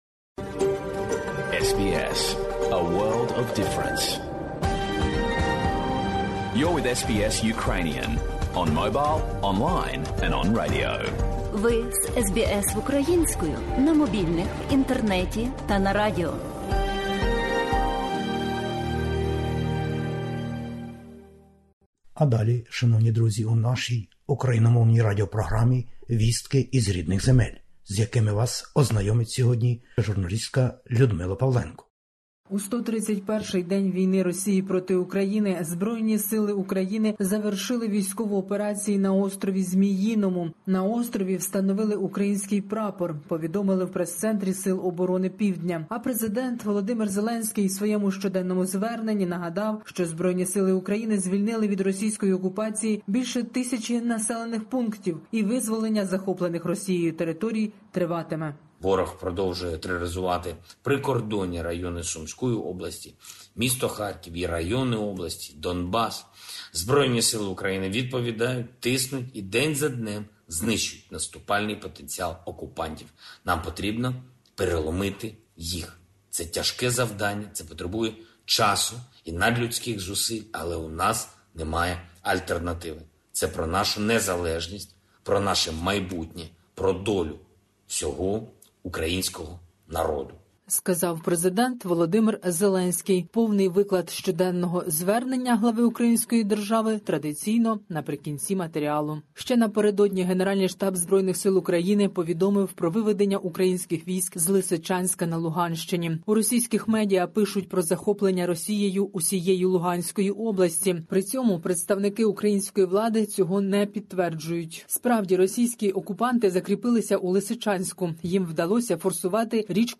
Добірка новин із героїчної України. 131-й день широкомасштабної військової аґресії російських збройних сил.